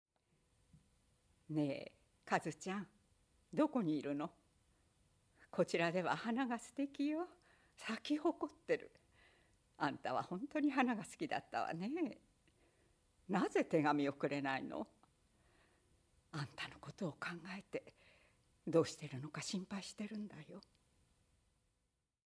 子供を心配する母
ボイスサンプル